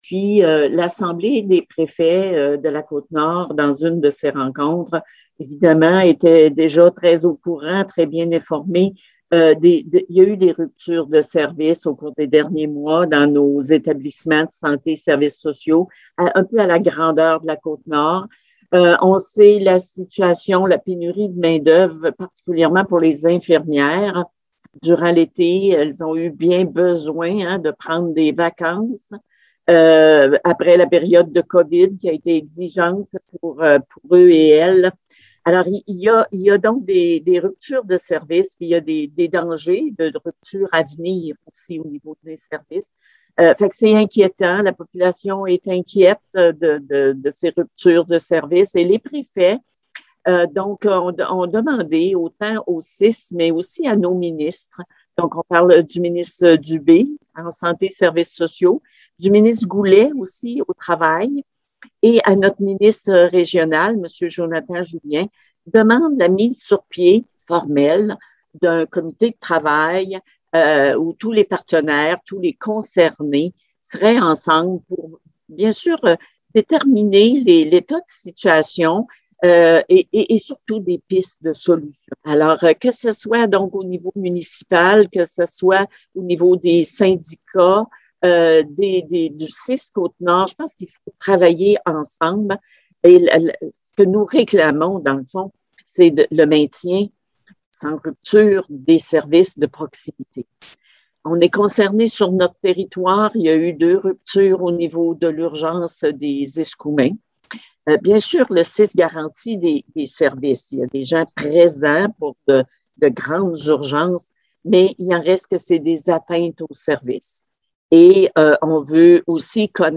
La préfet de la MRC et mairesse de Forestville, madame Micheline Anctil, a acceptée de nous faire un résumé de la séance en détaillant les points importants de la rencontre.